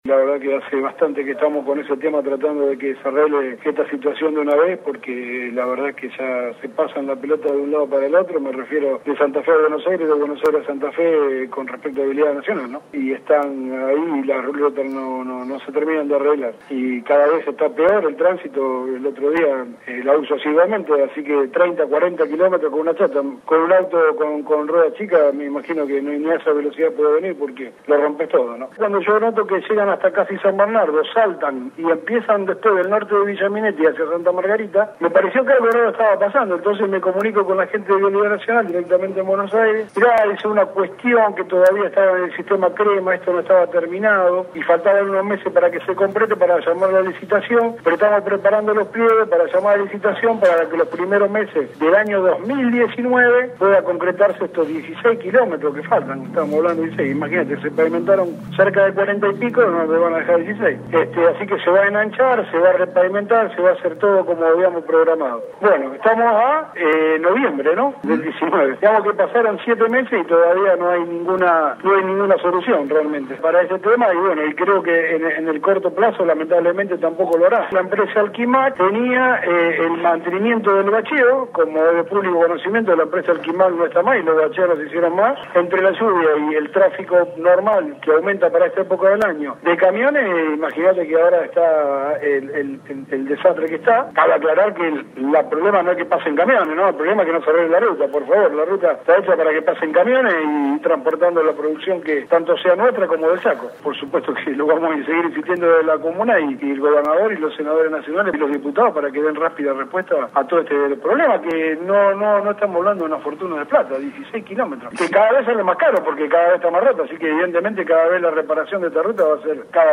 Gabriel Gentili, presidente comunal de Villa Minetti, también hizo referencia a este pedido y lamentó la no reparación de ese tramo de la 95: